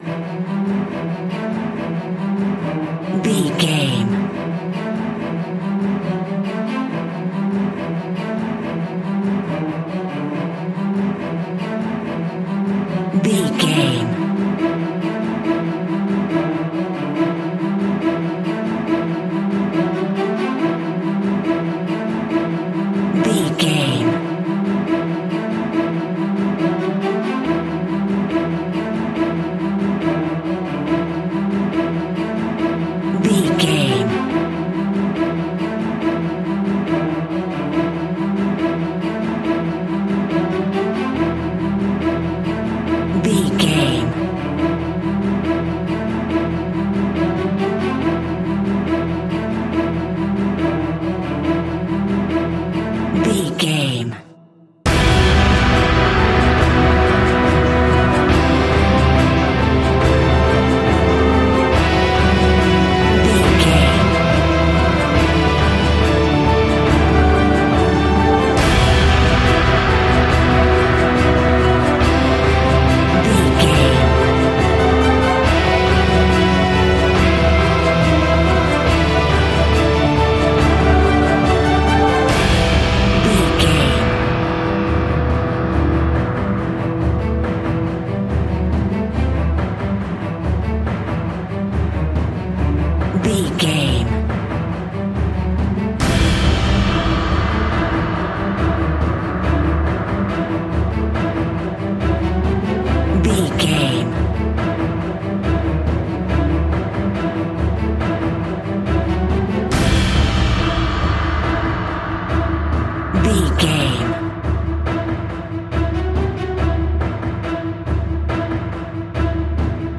Movie Opening for a Dramatic Epic Film.
In-crescendo
Thriller
Aeolian/Minor
ominous
suspense
eerie
intense
powerful
strings
brass
percussion
violin
cello
double bass
cinematic
orchestral
heroic
booming
cymbals
viola
french horn trumpet
taiko drums
timpani